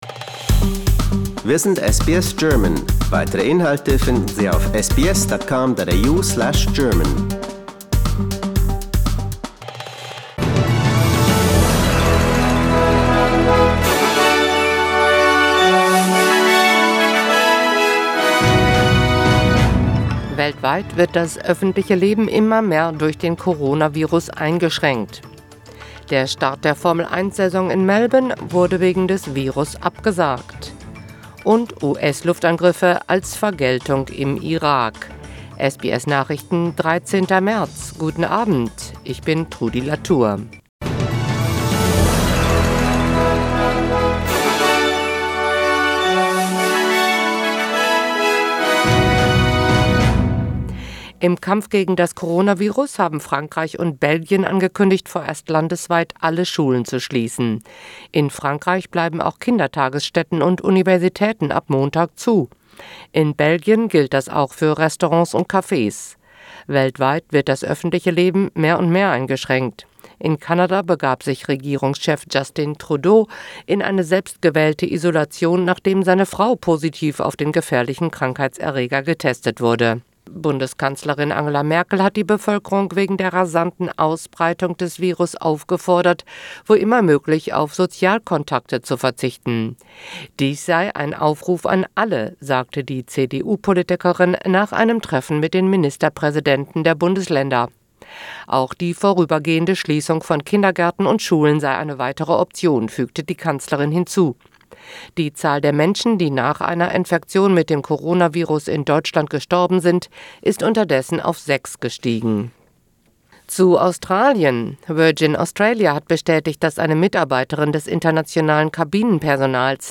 SBS Nachrichten, Freitag 13.3.2020